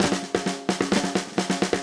AM_MiliSnareA_130-03.wav